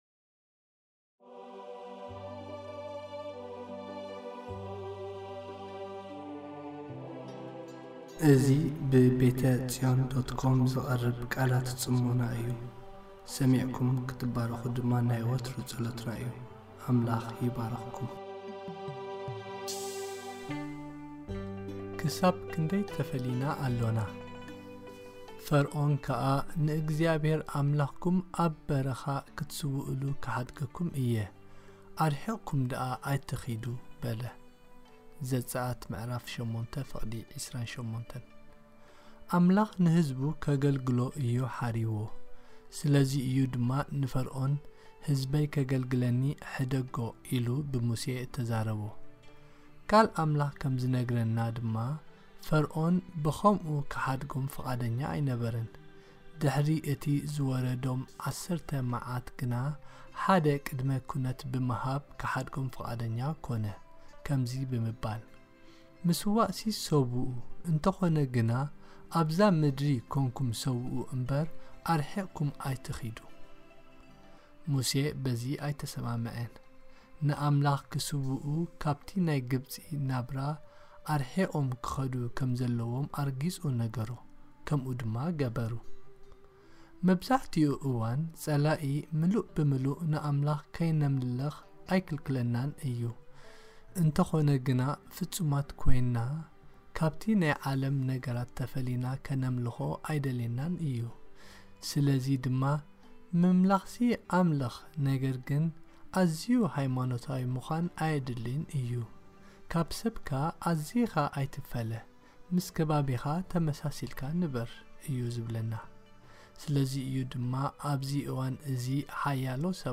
Devotional posted by